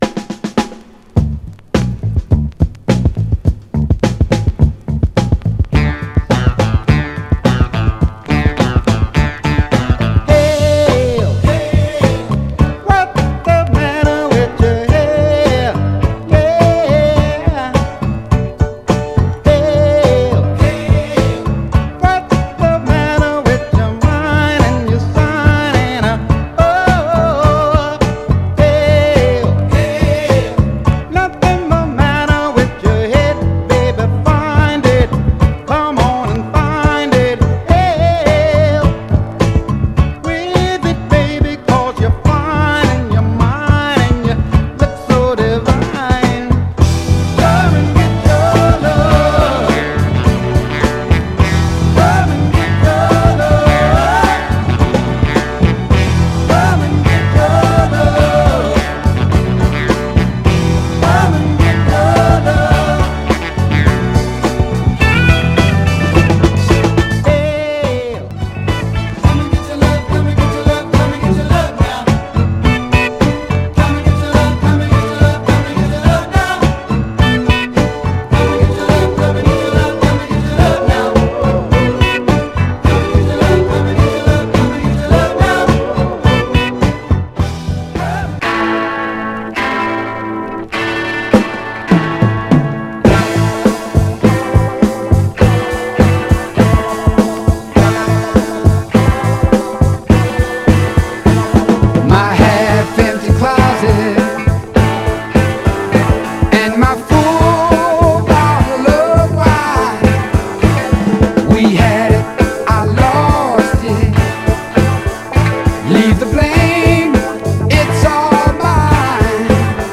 同系のメロウな込み上げフックながら、よりトライバルなファンク色が強い、
序盤で少しチリつきますが、目立つノイズは少なく全体的にはプレイ概ね良好です。
※試聴音源は実際にお送りする商品から録音したものです※